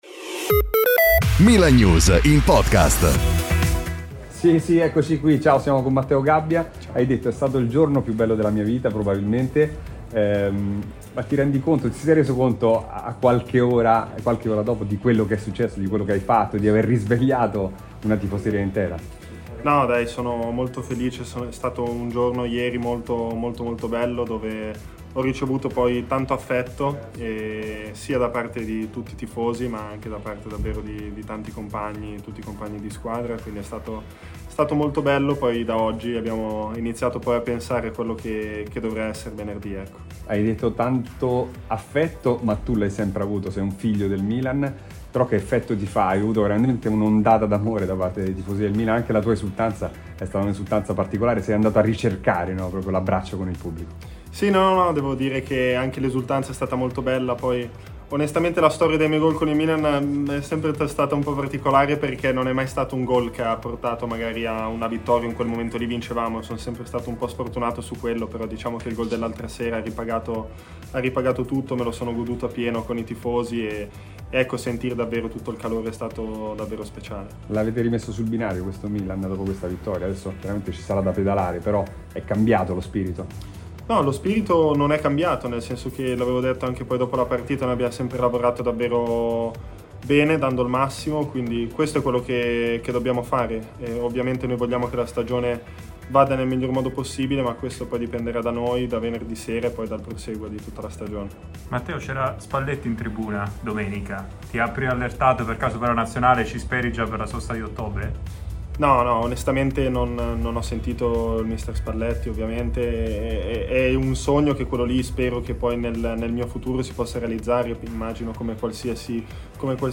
Prima si è concesso a telecamere e microfoni della stampa per qualche dichiarazioni.